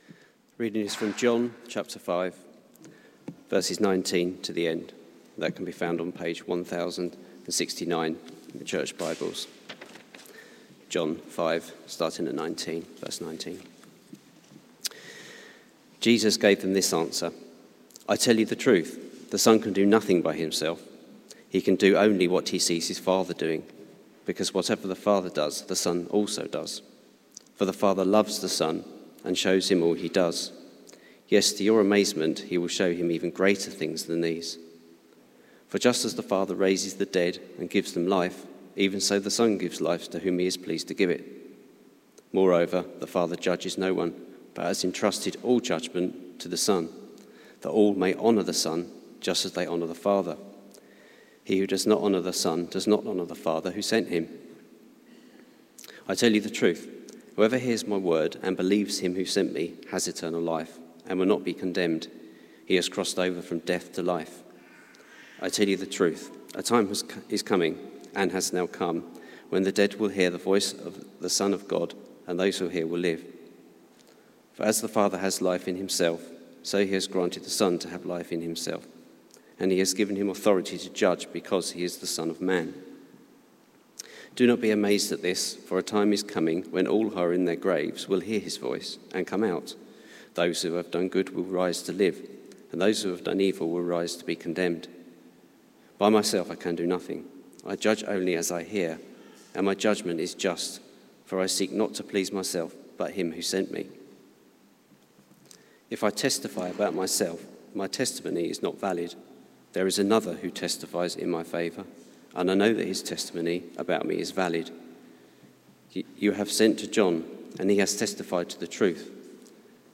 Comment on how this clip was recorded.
Media for Service (10.45) on Sun 03rd Nov 2024 10:45